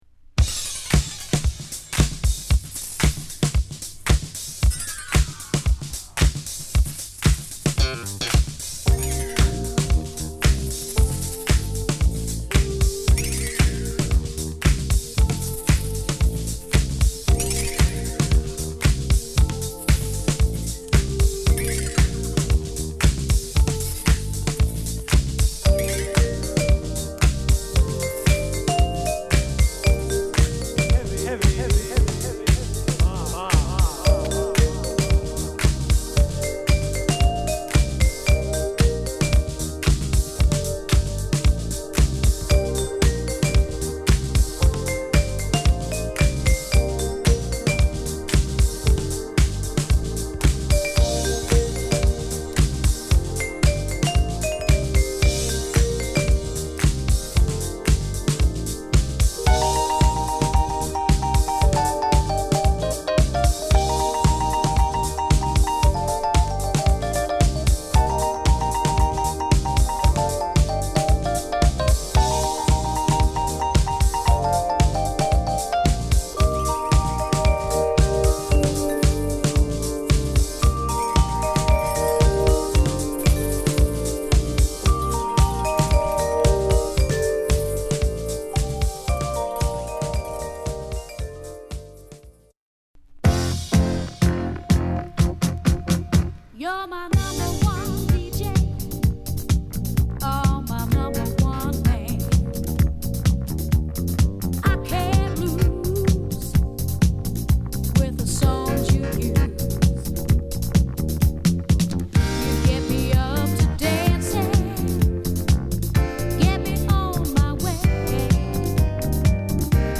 フィリー・ソウルを下敷きにヴィブラフォンが絡む